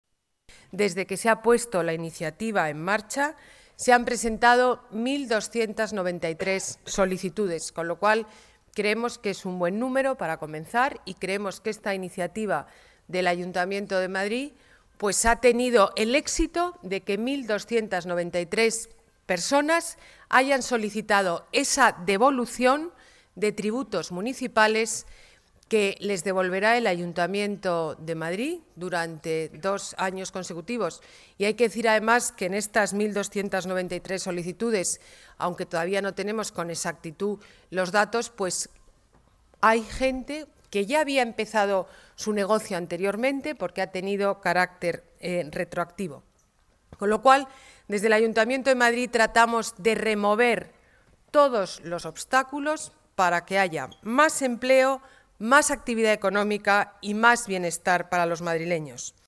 Nueva ventana:Declaraciones de la alcaldesa de Madrid, Ana Botella